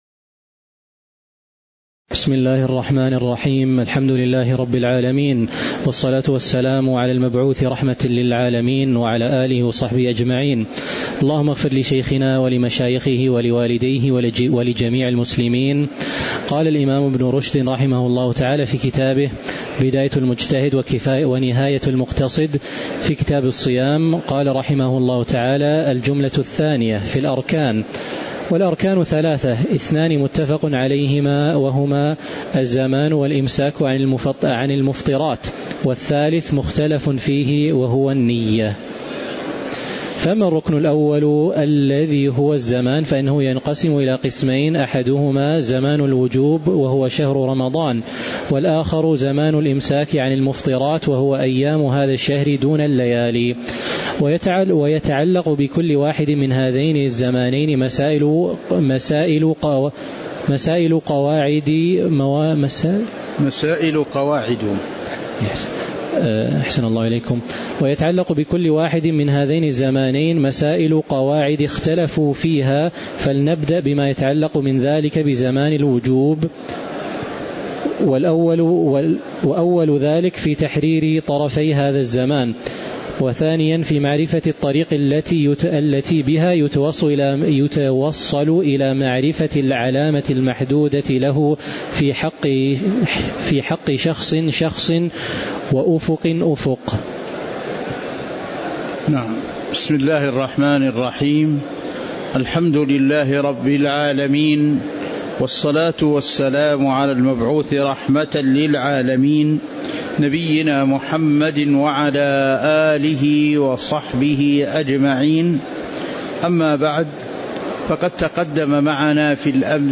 تاريخ النشر ٢٣ شعبان ١٤٤٥ هـ المكان: المسجد النبوي الشيخ